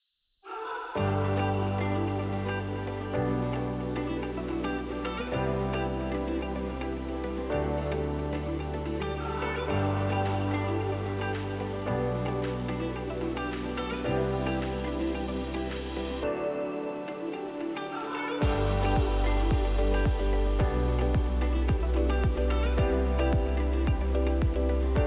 Music-On-Hold Options